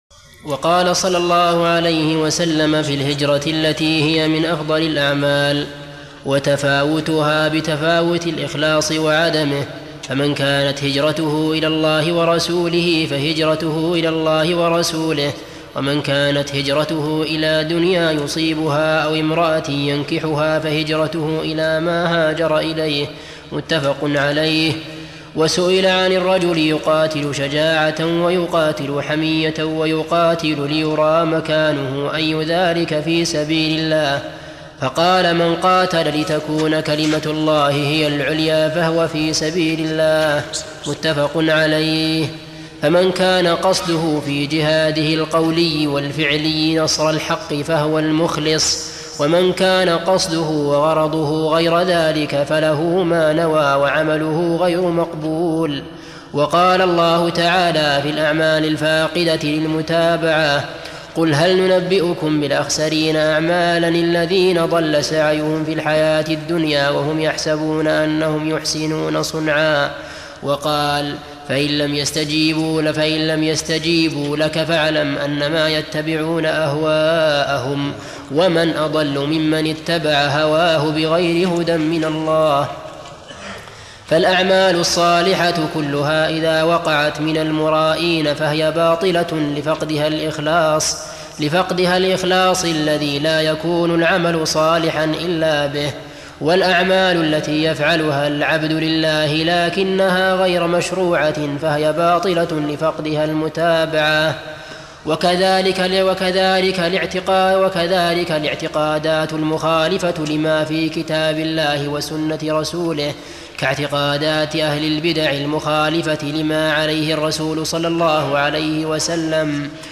ارسل فتوى عبر "الواتساب" ينبوع الصوتيات الشيخ محمد بن صالح العثيمين فوائد من التعليق على القواعد والأصول الجامعة - شرح الشيخ محمد بن صالح العثيمين المادة 17 - 148 تابع القاعدة الخامسة إخلاص النيّة لله في العبادات...